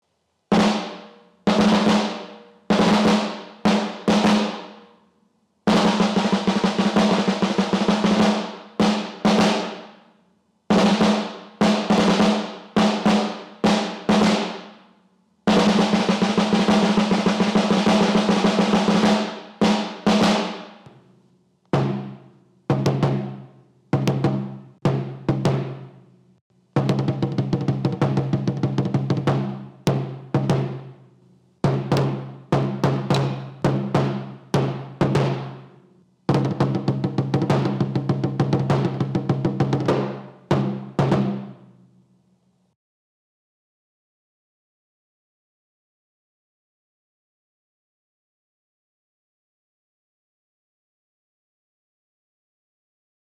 redoble-caja-MSF-1-CAJA_PEQUENYA_2.wav